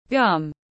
Nướu tiếng anh gọi là gum, phiên âm tiếng anh đọc là /ɡʌm/.
Gum /ɡʌm/